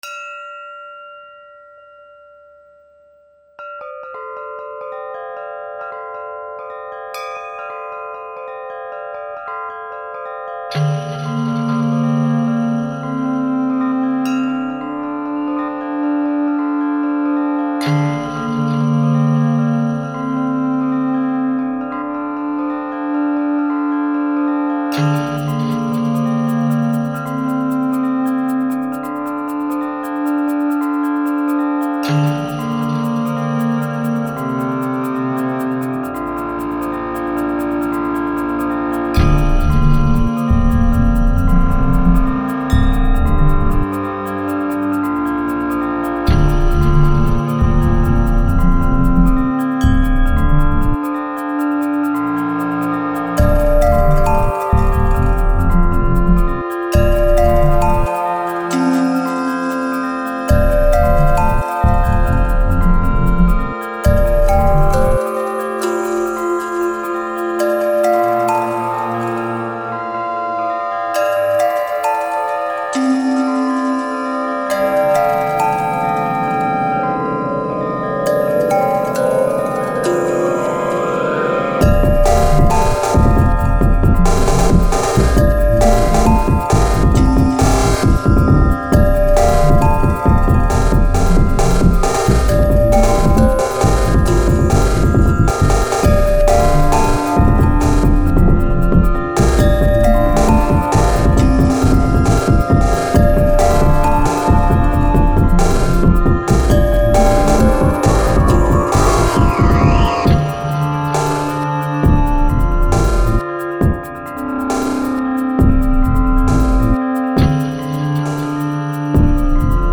IDM